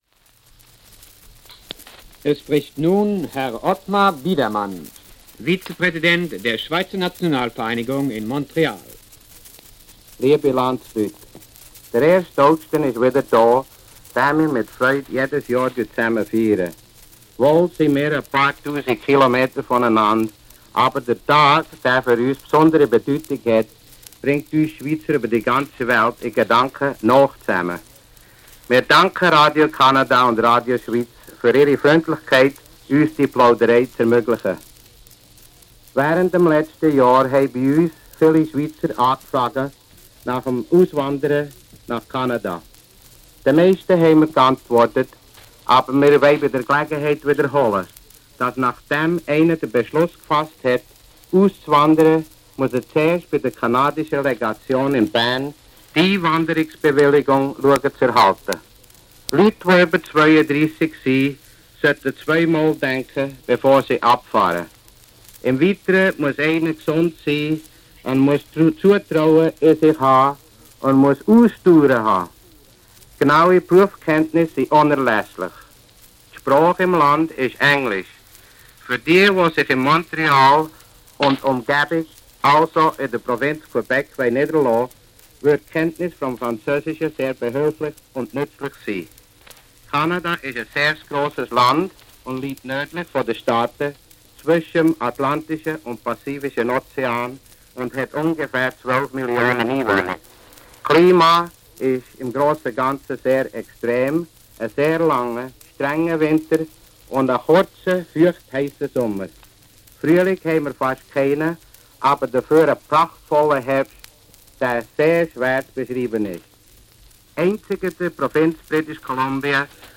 Die Rede wurde anlässlich des Nationalfeiertags am 1. August im Radiostudio Montreal auf 78-Touren-Schallplatten aufgezeichnet.